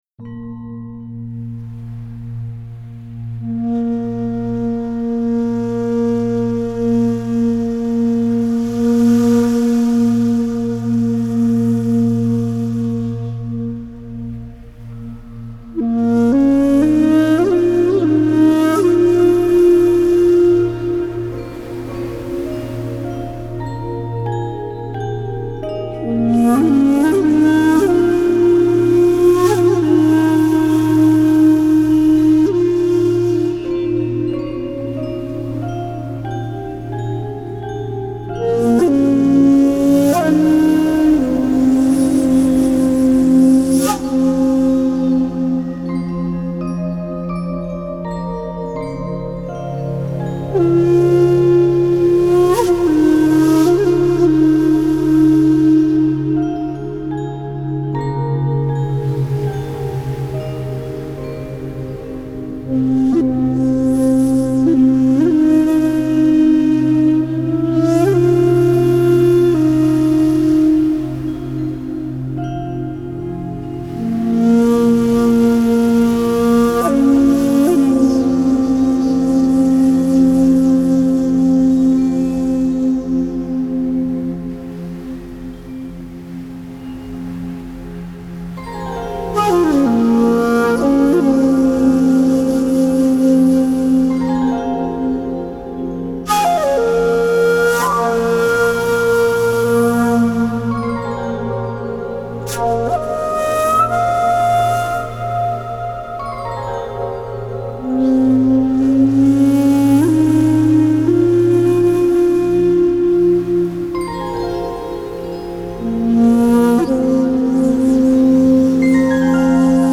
موسیقی کنار تو
آرامش بخش , بومی و محلی , مدیتیشن , موسیقی بی کلام
موسیقی بی کلام فلوت